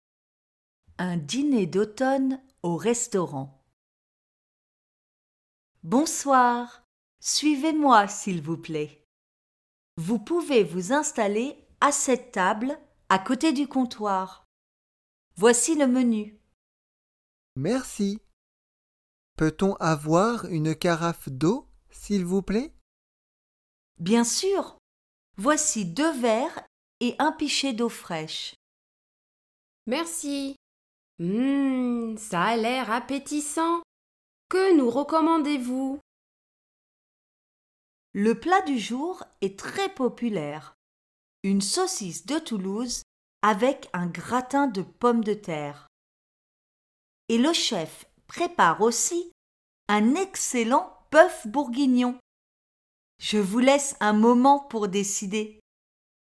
Reader-diner-d-automne-au-restaurant-1-2.mp3